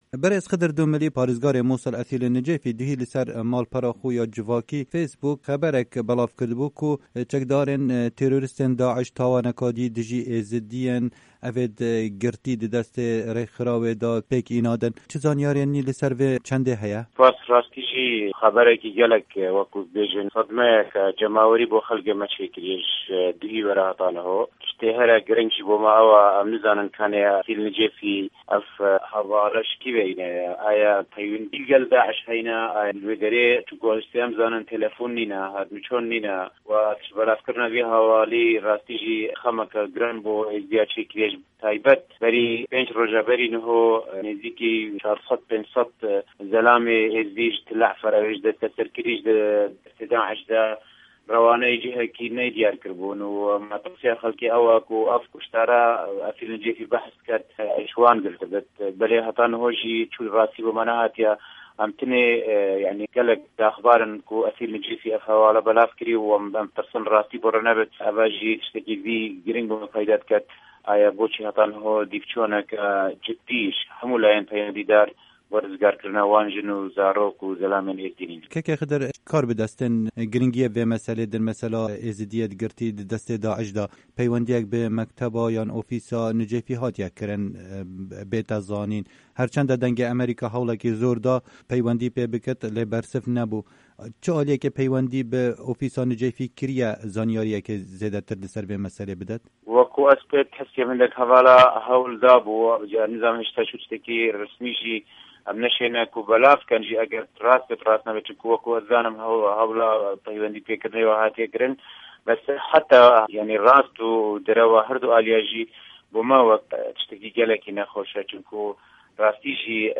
hevpeyvînekê